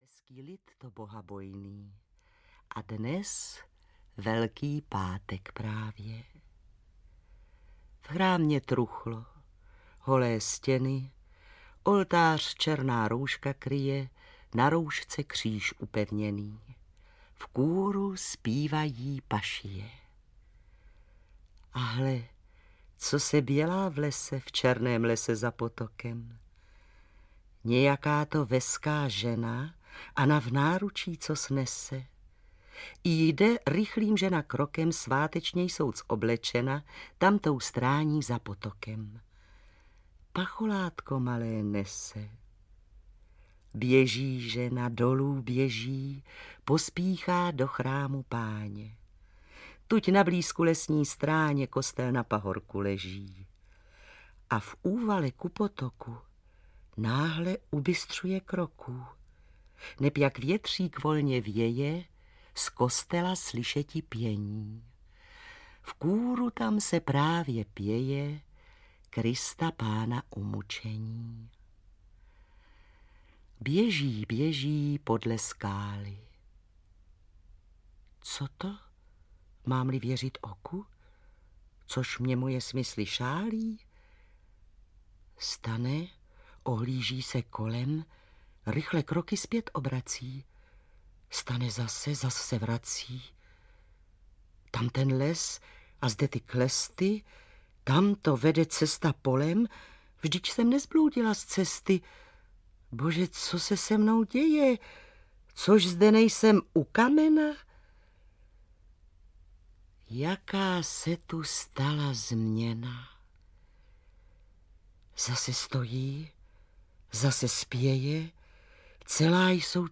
Kytice audiokniha
Erben se Kyticí z pověstí národních stal výsostným básníkem symbolu a mýtu...Jde o dvě skupiny recitátorů: jedni natočili Erbenovy básně v prosinci 1961, druzí v září 1983.
Zároveň jsme přihlíželi k tomu, aby se týž interpret neobjevil na jedné straně desky dvakrát a aby střídání mužských a ženských hlasů mělo stavebnou logiku.
• InterpretMiroslav Doležal, Vlasta Fabianová, Antonie Hegerlíková, Julie Charvátová, Ota Sklenčka, Zdeněk Štěpánek, Marie Vášová, Václav Voska